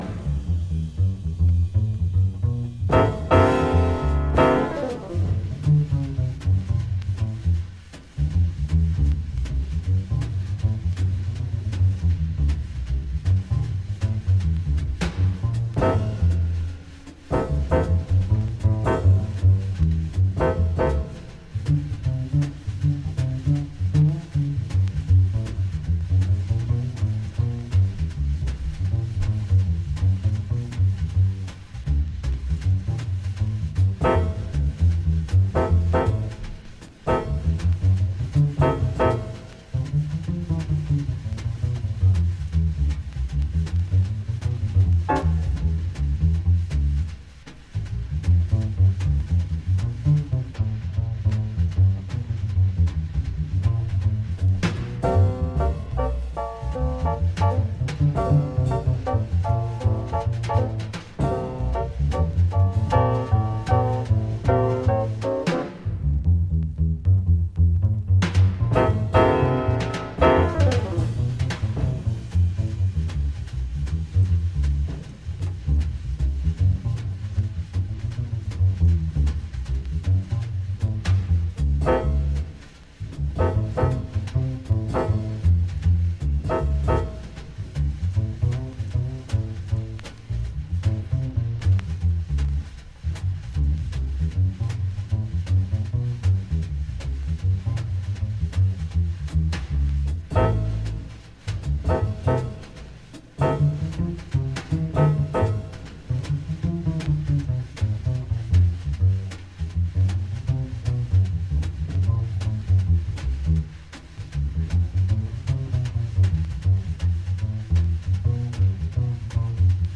Classique & jazz